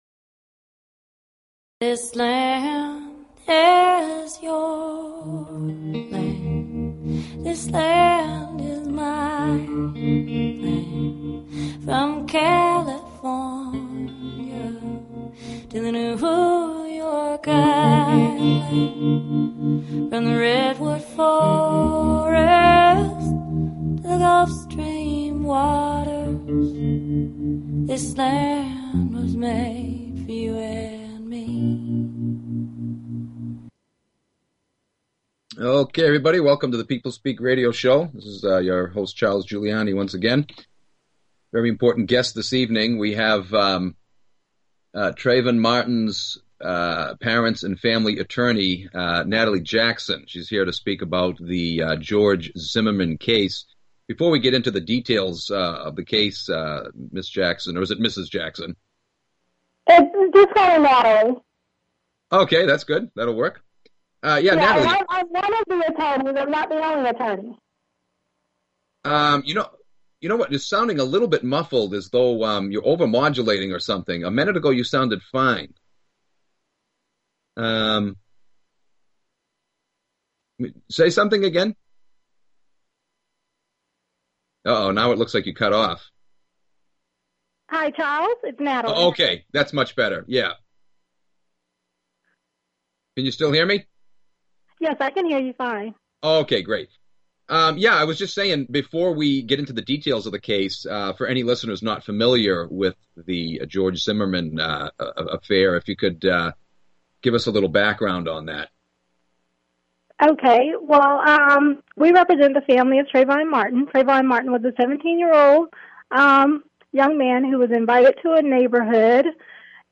Talk Show Episode
Nationwide audience participation during the show.